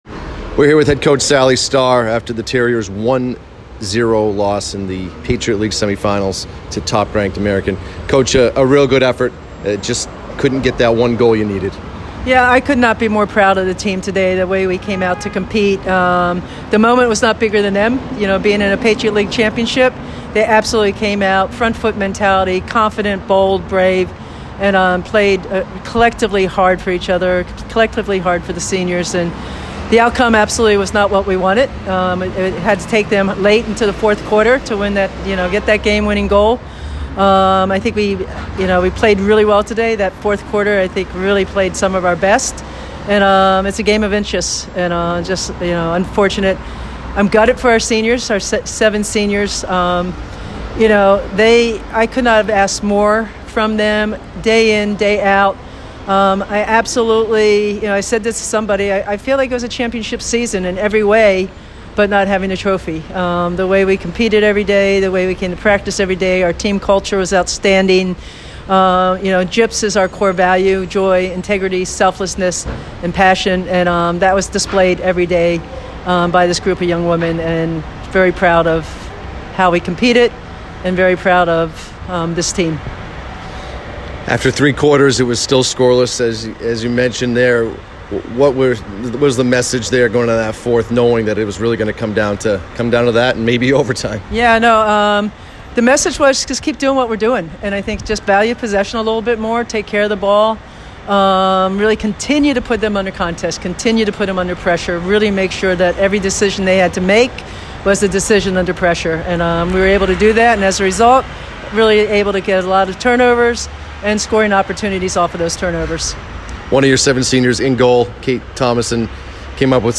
Field Hockey / American Postgame Interview